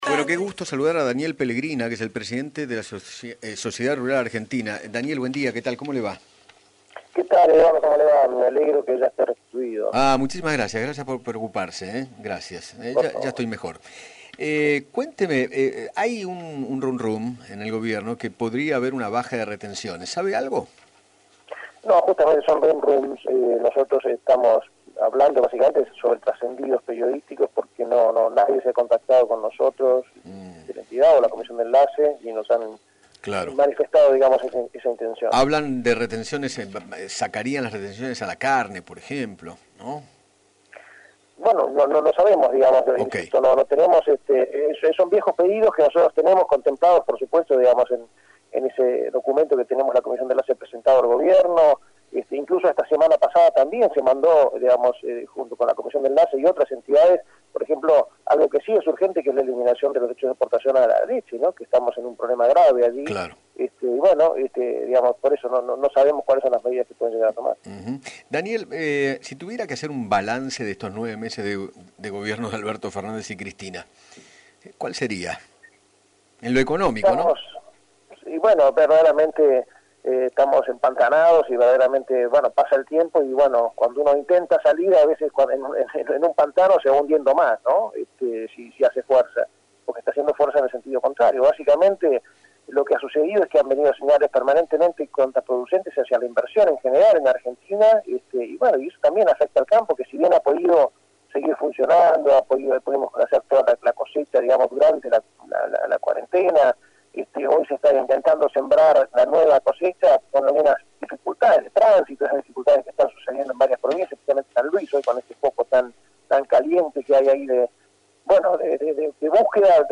dialogó con Eduardo Feinmann sobre los rumores de las medidas que podría tomar el Gobierno